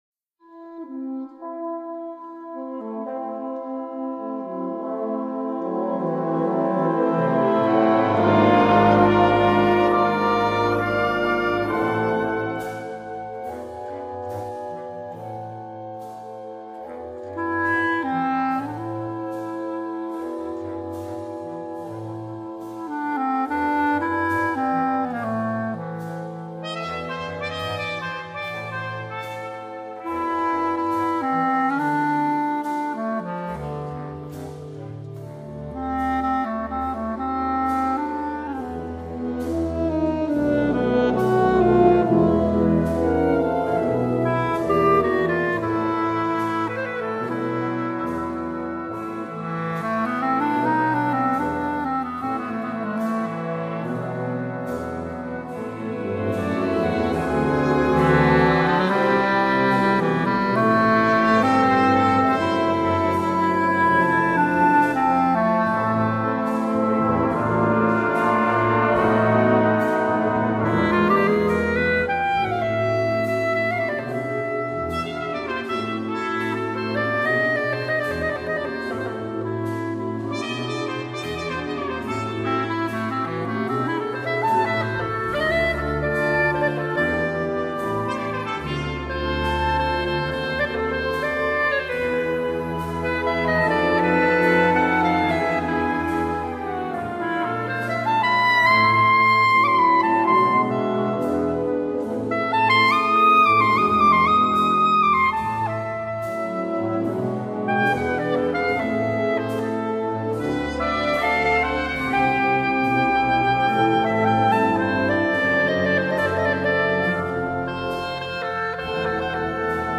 Organico: Duo di Clarinetti